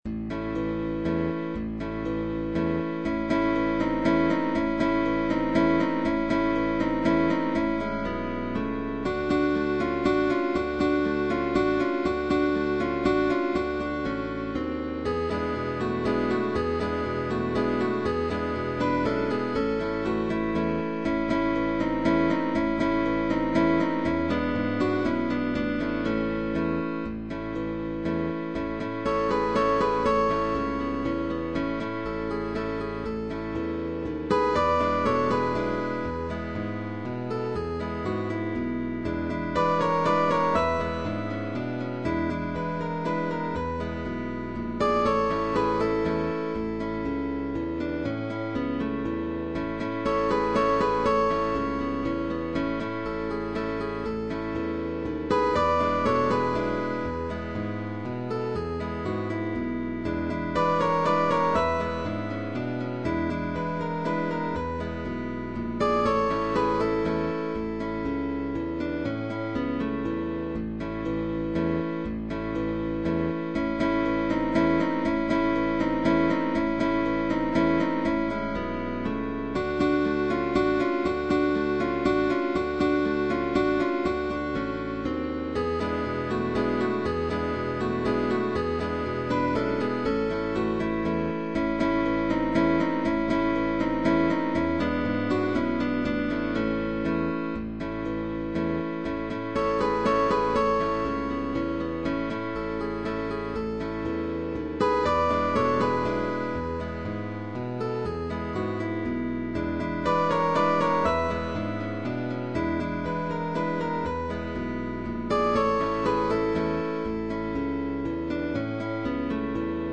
Guitar trio sheetmusic.
GUITAR TRIO